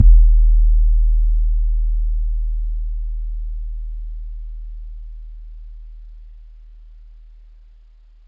Southside 808 (21).wav